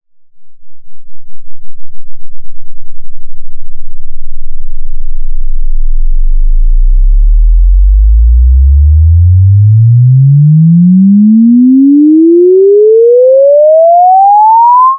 logsvep.wav